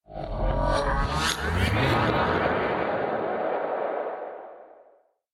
Звук искажения nШум деформации nДеформированный звук nЗвучание деформации